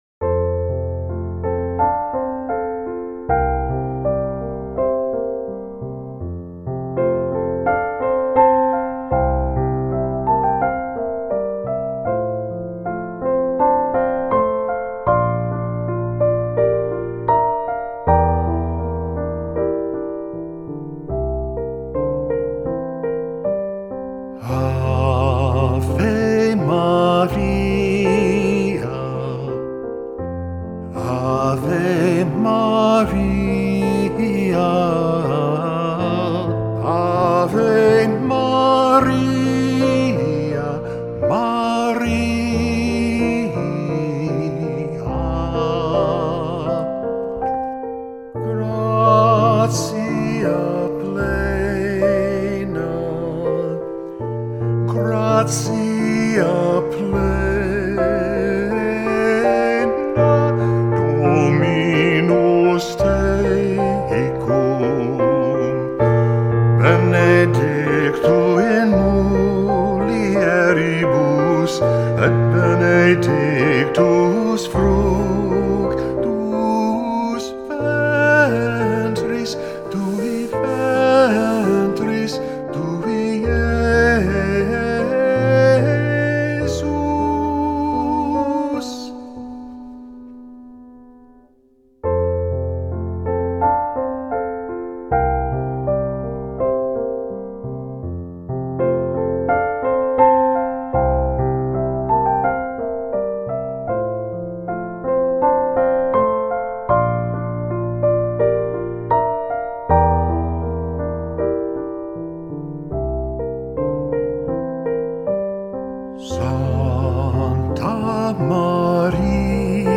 classical, inspirational, contemporary
F major
♩=80 BPM